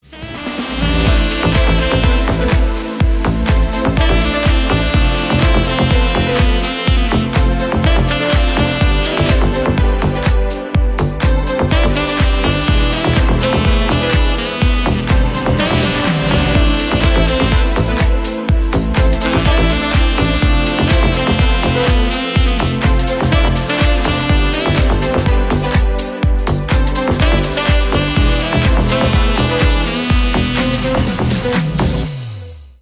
Танцевальные
без слов # кавер